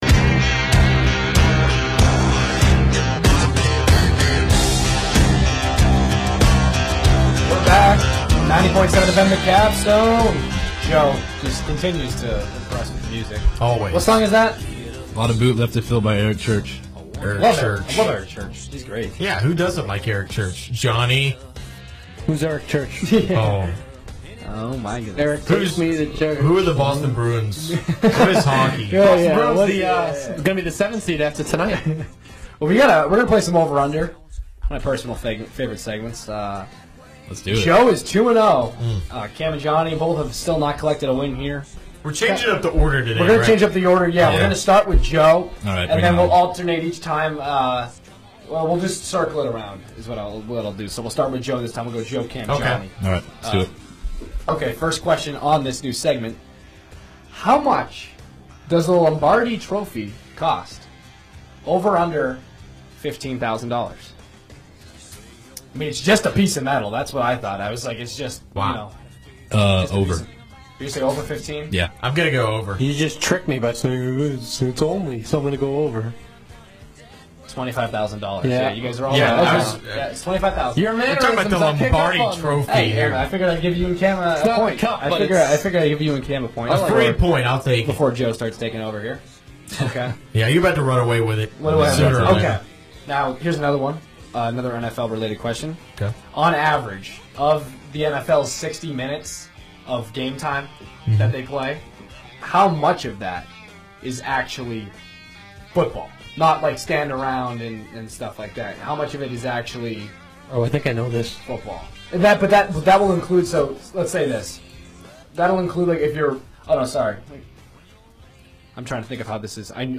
WVUA-FM's and Tuscaloosa's longest running sports talk show "The Student Section"